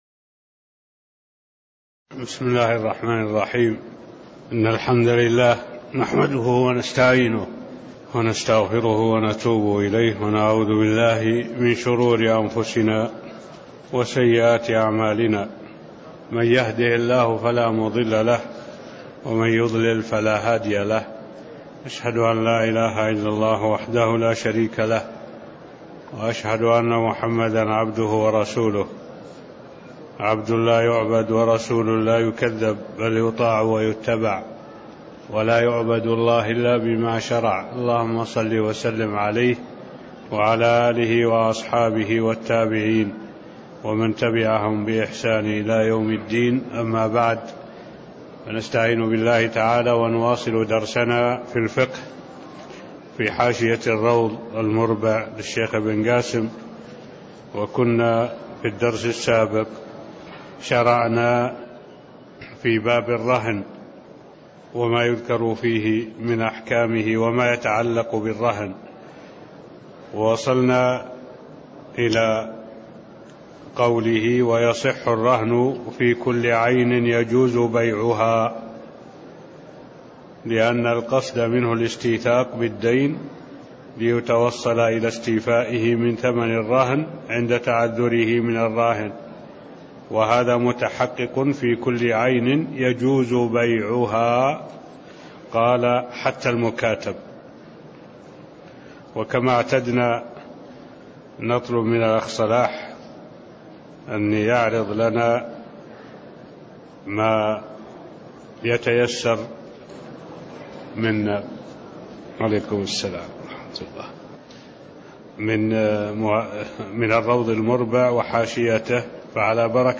المكان: المسجد النبوي الشيخ: معالي الشيخ الدكتور صالح بن عبد الله العبود معالي الشيخ الدكتور صالح بن عبد الله العبود قوله: (ويصح الرهن في كل عين يجوز بيعها) (02)